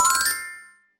level-win.mp3